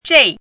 chinese-voice - 汉字语音库
zhei4.mp3